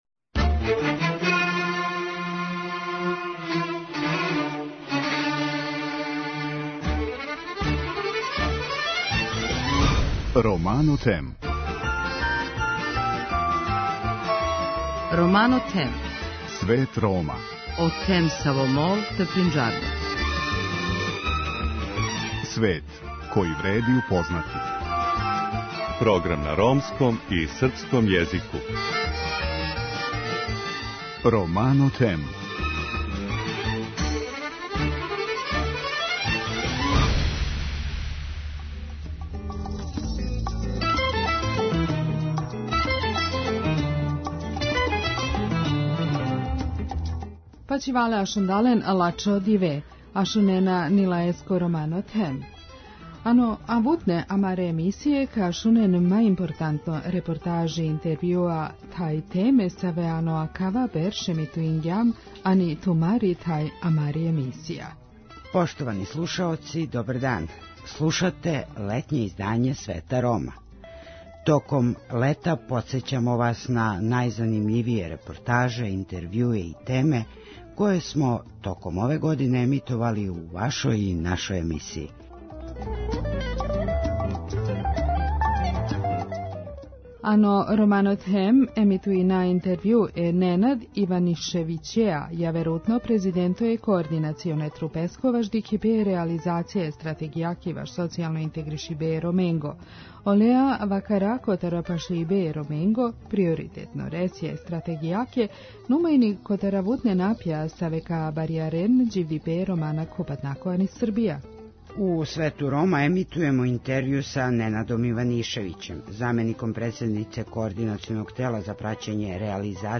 У „Свету Рома” емитујемо интервју са Ненадом Иванишевићем, замеником председнице Координационог тела за праћење реализације Стратегије за социјално укључивање Рома и Ромкиња.